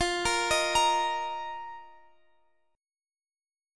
F7sus4 Chord (page 4)
Listen to F7sus4 strummed